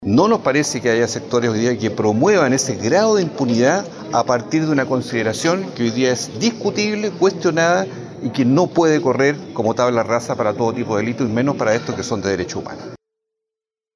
El senador socialista, Juan Luis Castro, advirtió que el debate no puede desligarse del contexto histórico, jurídico y de las obligaciones internacionales del Estado de Chile.